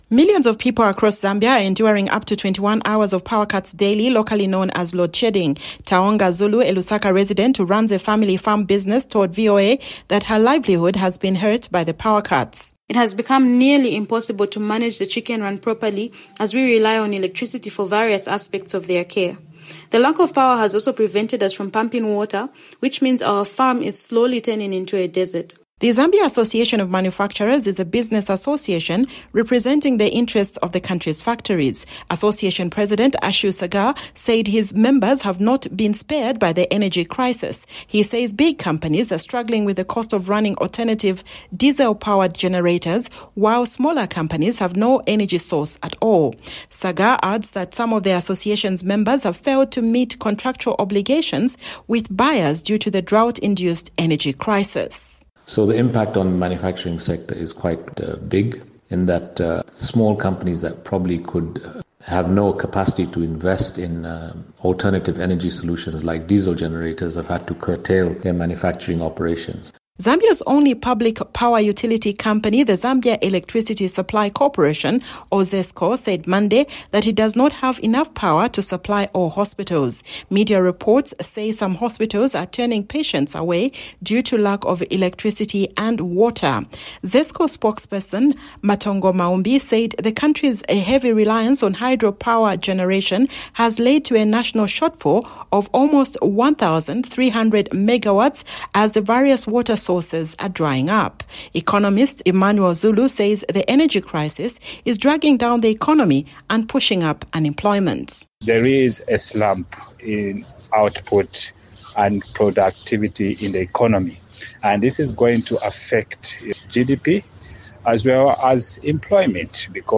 reports from the country’s capital, Lusaka